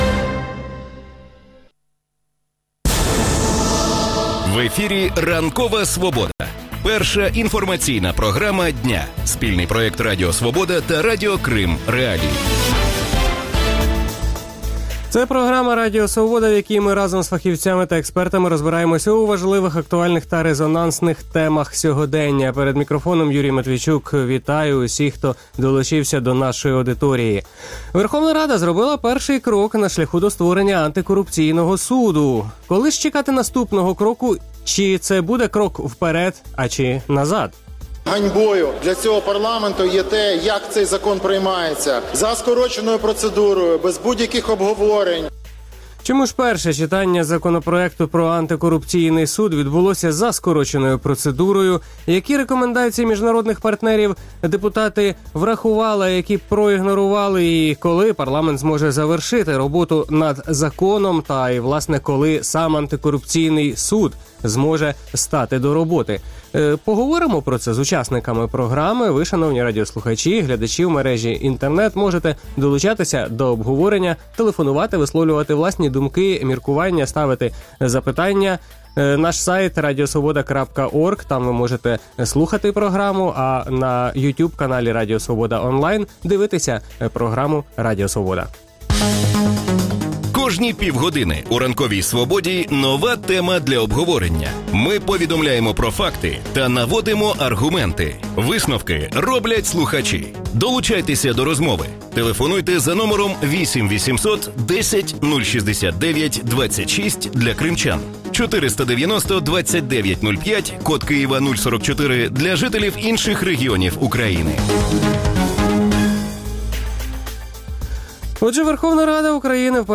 Чому перше читання закону про антикорупційний суд відбулося за скороченою процедурою? Які рекомендації міжнародних партнерів депутати проігнорували? Коли парламент зможе завершити роботу над законом? У Ранковій Свободі про це говоритимуть народний депутат (позафракційний») Дмитро Добродомов та народний депутат («Блок Петра Порошенка») Олег Барна.